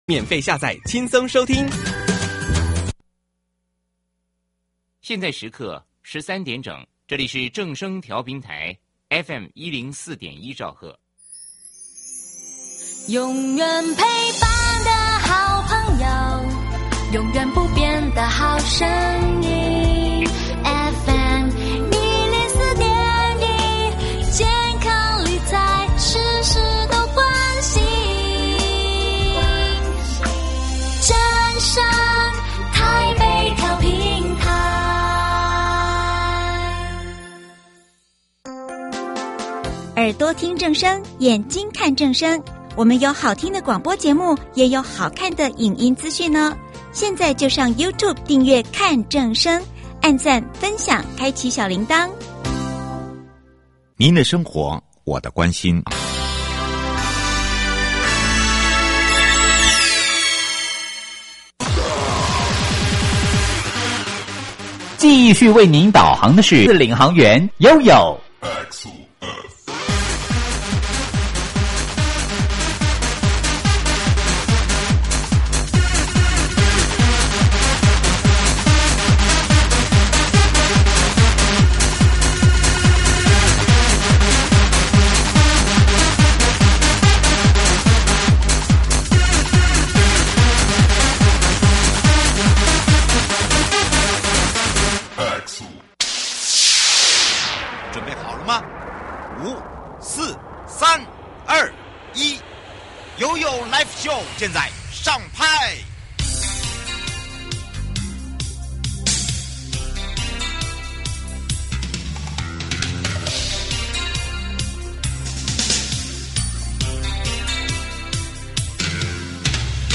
1.國土署都市基礎工程組